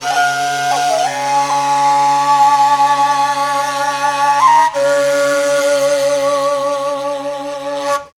TIBETDRON1-L.wav